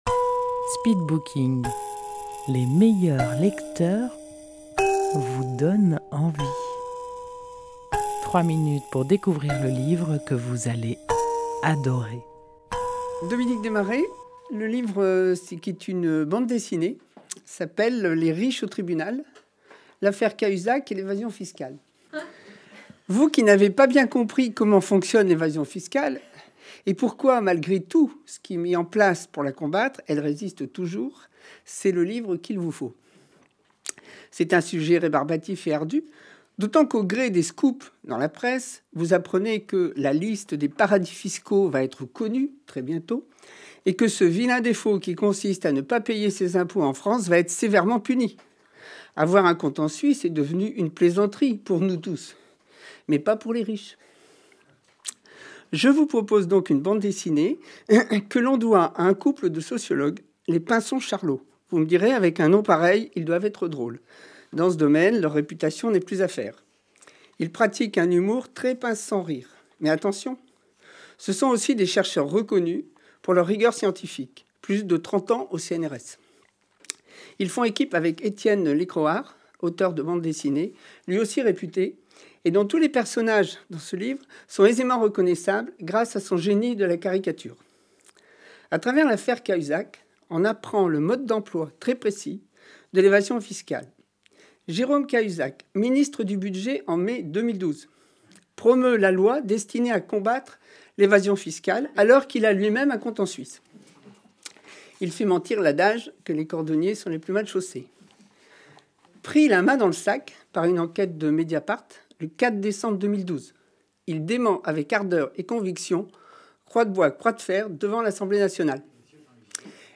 Rendez-vous au speed booking : les meilleurs lecteurs vous font partager leur passion pour un livre en 3 minutes chrono. Enregistré en public au salon de thé Si le cœur vous en dit à Dieulefit.